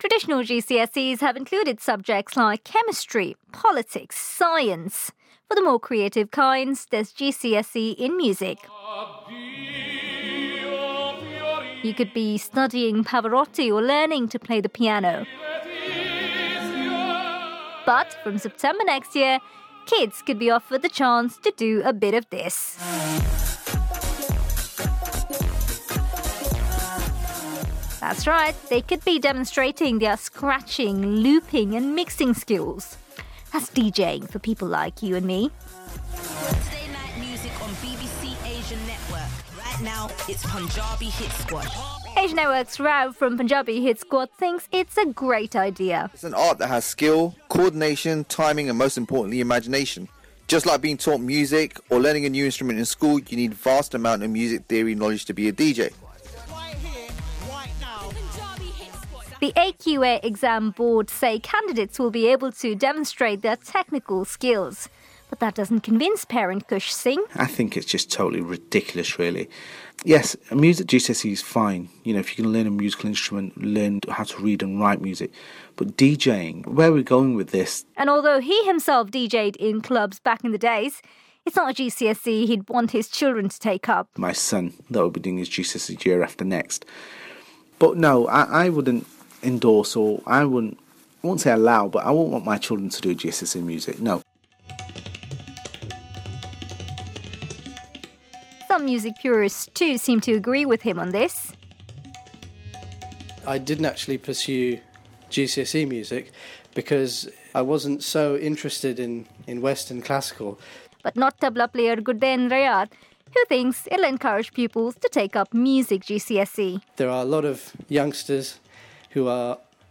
My report for Asian Network.